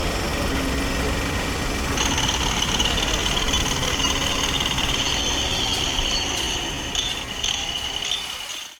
construction.ogg